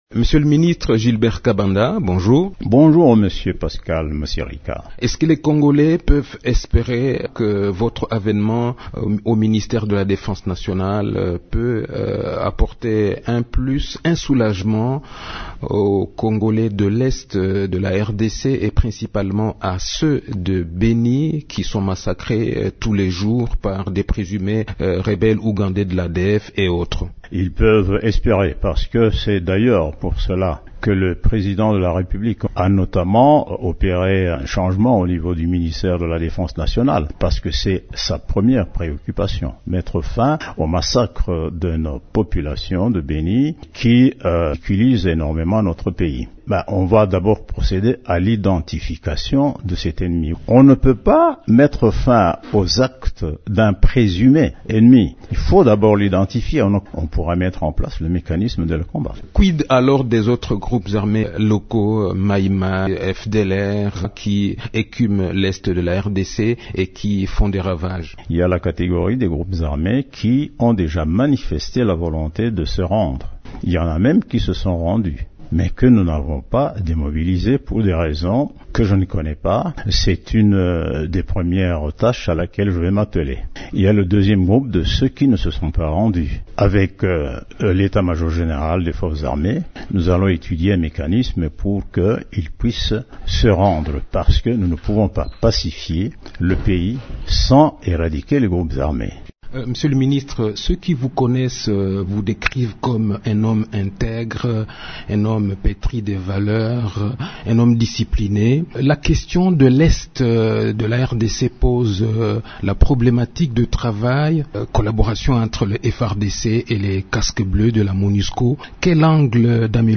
Gilbert Kabanda Kurhenga, ministre de la Défense nationale et anciens combattants, promet de placer la restauration de l’éthique au sein de l’armée au centre de son action. Il l’a dit au cours d’une interview exclusive accordée le 18 avril à Radio Okapi.